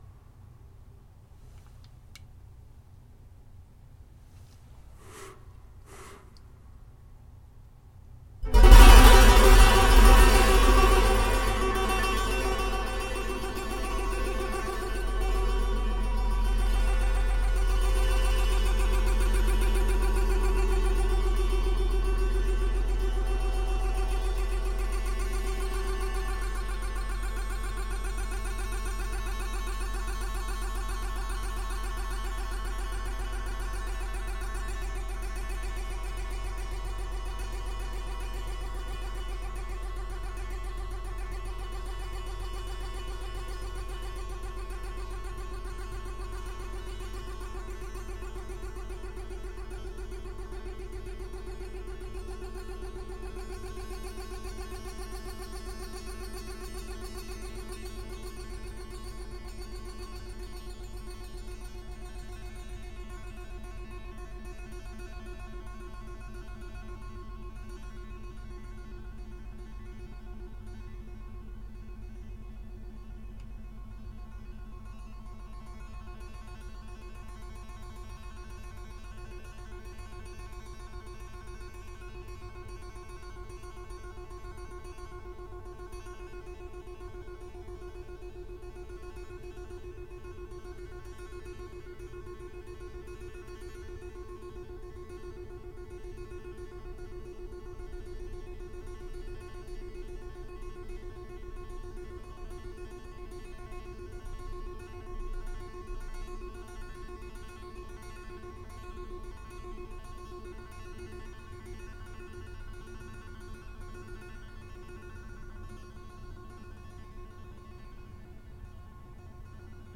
• Another example of a sound created using only studio recording, specifically close recording of a very quiet sound to amplify it and change its effect:
Bottle Rocking on Piano Strings – Another example of a sound created using only studio recording
5.-long_rocking_stereo.m4a